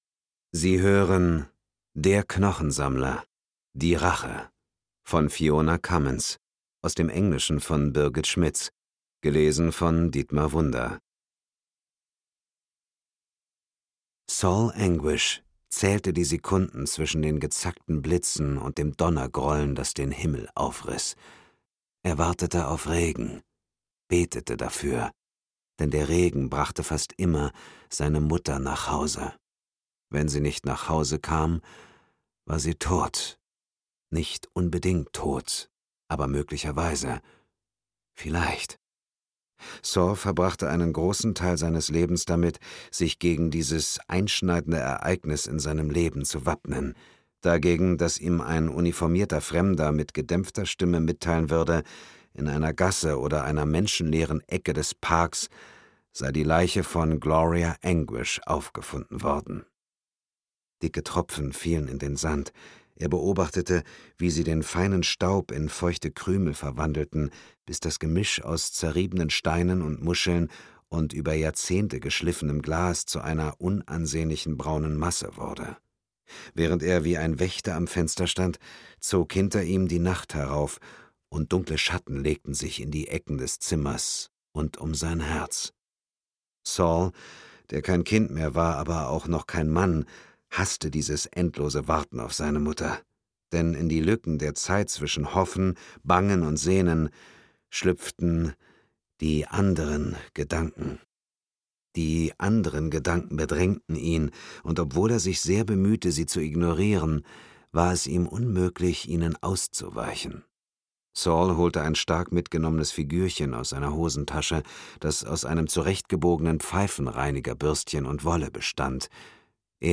Dietmar Wunder (Sprecher)
Dietmar Wunder ist einer der profiliertesten Synchronsprecher und Dialogregisseure und als deutsche Stimme von James-Bond-Darsteller Daniel Craig bekannt. Mit seiner markanten, sehr wandlungsfähigen Stimme ist er ein äußerst beliebter Hörbuchsprecher, der nuancenreich Spannung vermitteln kann.